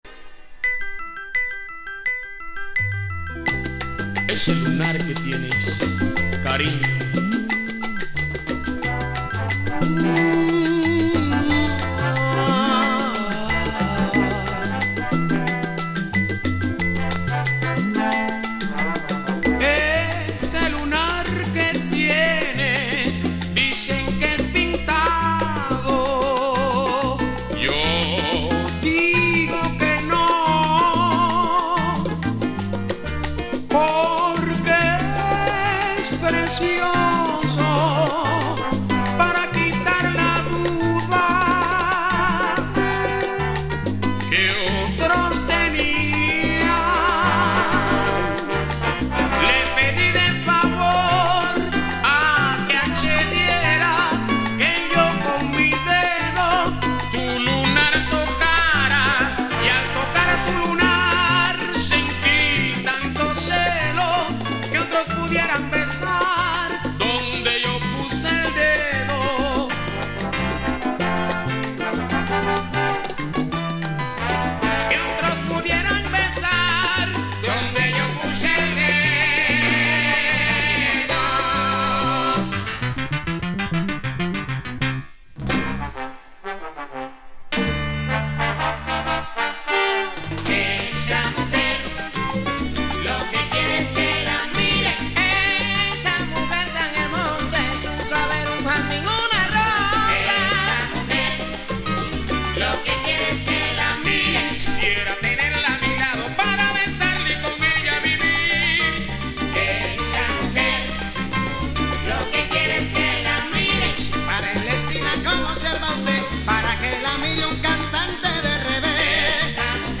チャングイという リズムで一世風靡した。